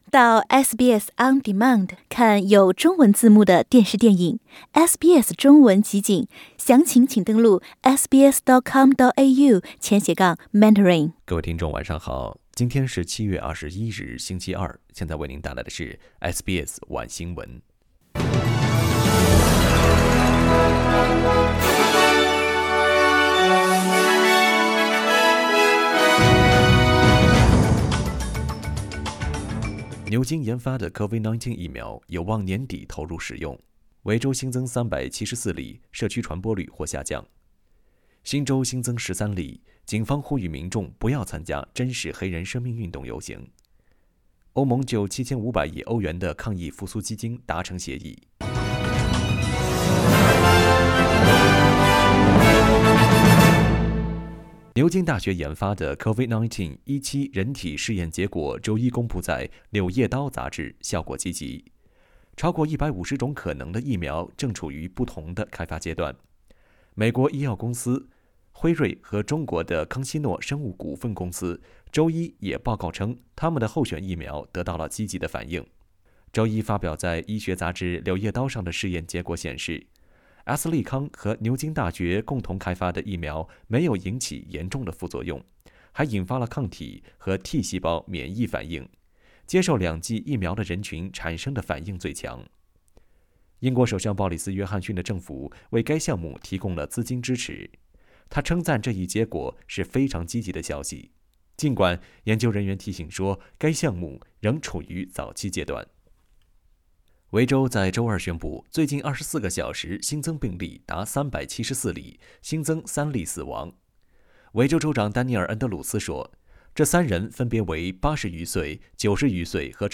SBS晚新闻（7月21日）